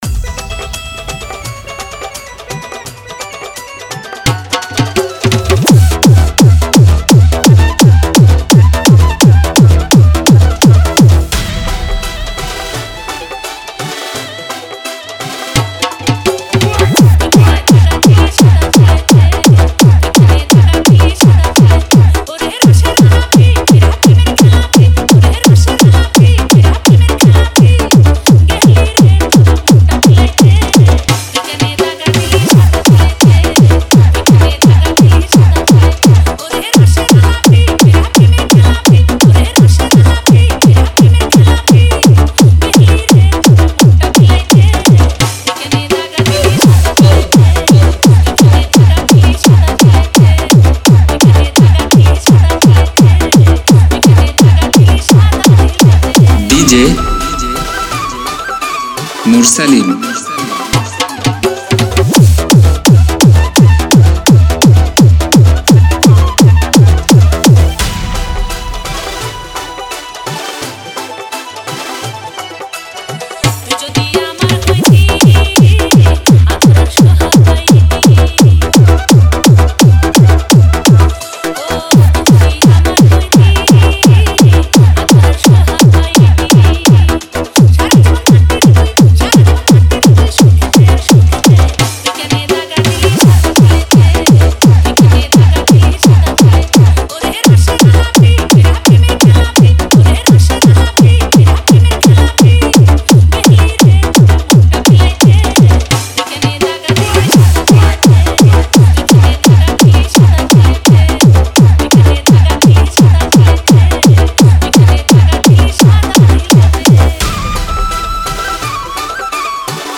Category : Bangla Remix Song